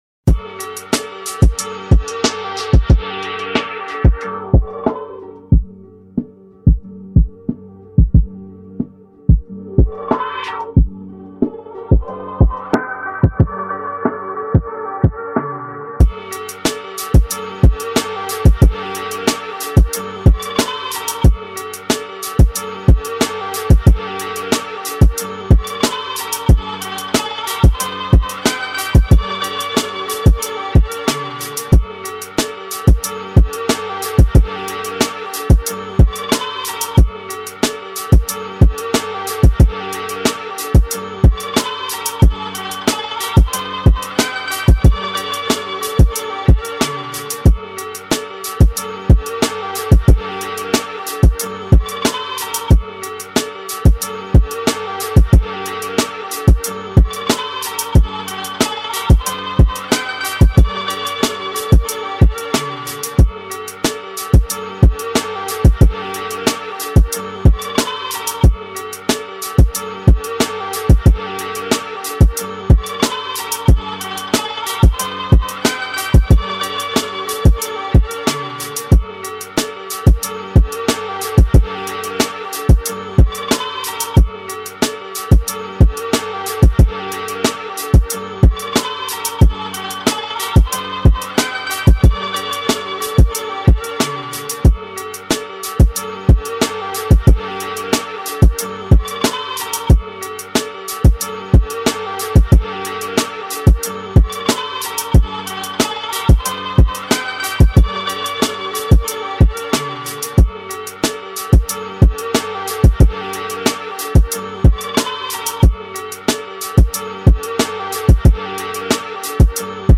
Here's the official instrumental
Rap Instrumental